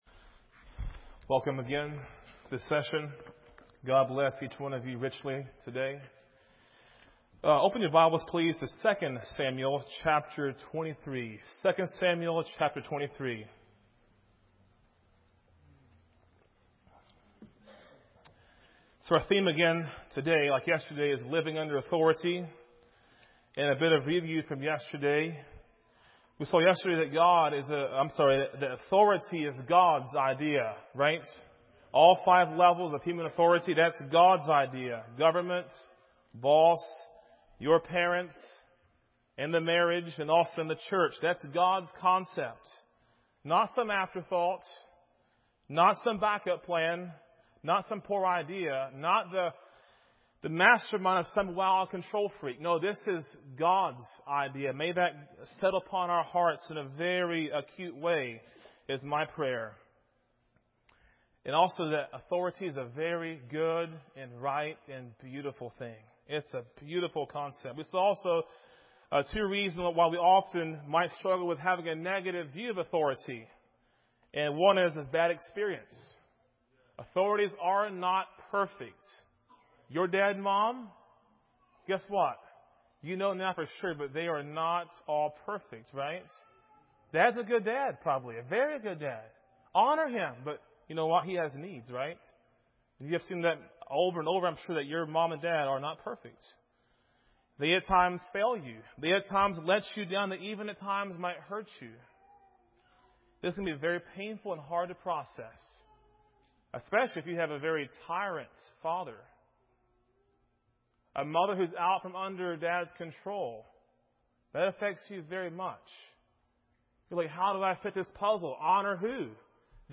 A message from the series "Bible Boot Camp 2021."